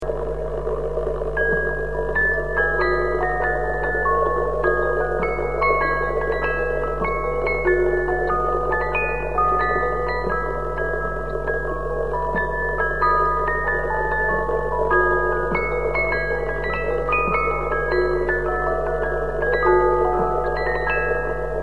Old Music Box EDELWEISS Records
Needless to say that the quality of these recordings is poor, if measured with nowadays (CD) standards.